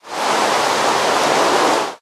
rain1.ogg